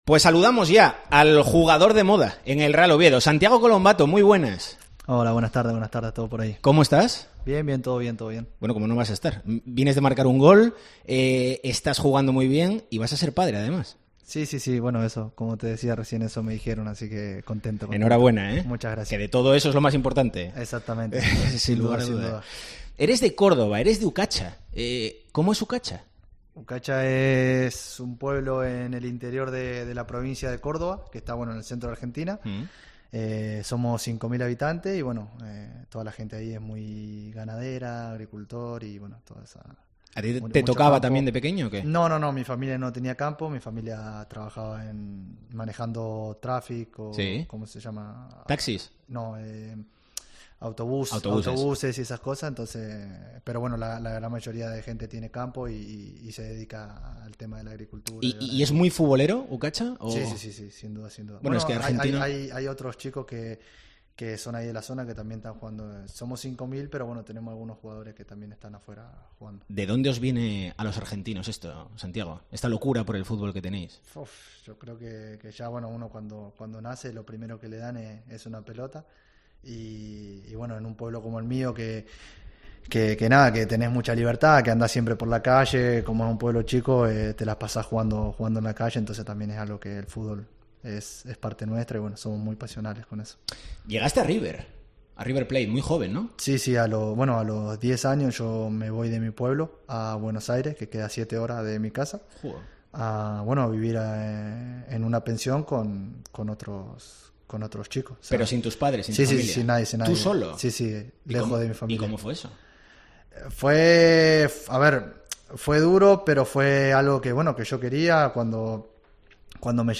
Entrevista a Santiago Colombatto en COPE Asturias